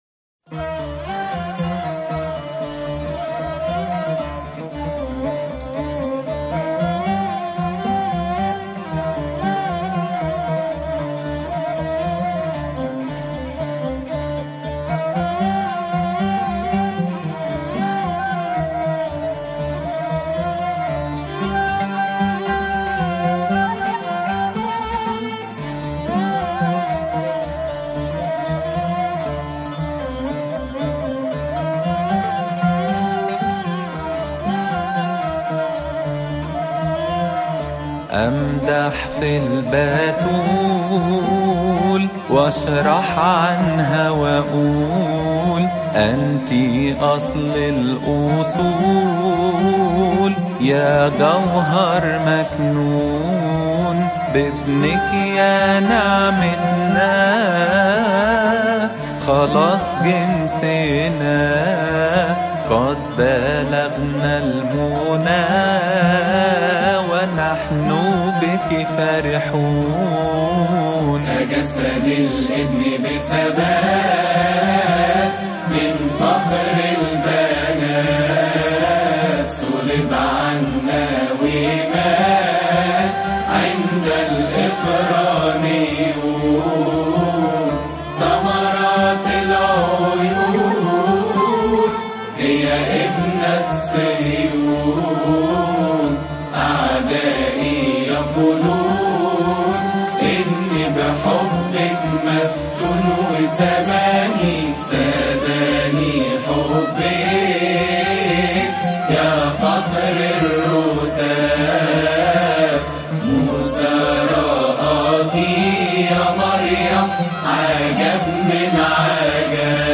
• المصدر : فريق يوتيرن
أمدح-في-البتول-فريق-يوتيرن-حفل-لحن-الكريسماس.mp3